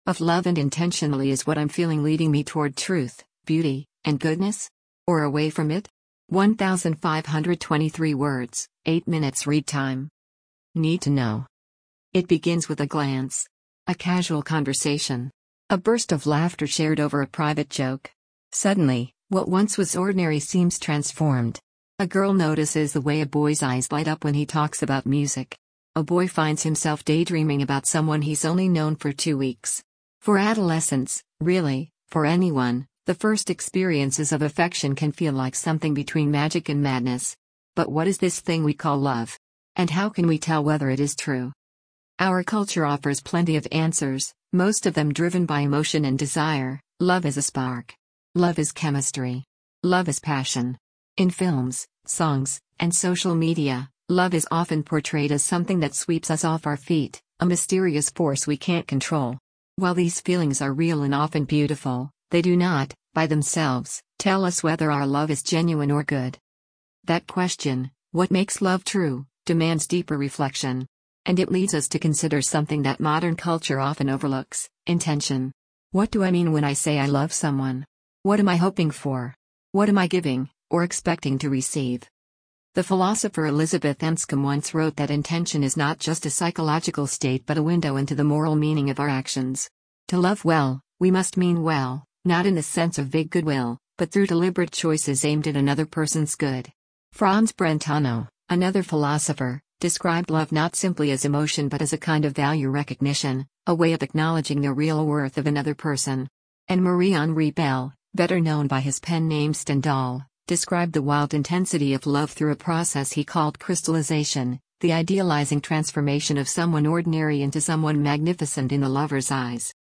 easytts_audio_Of-Love-And-Intentionality1.mp3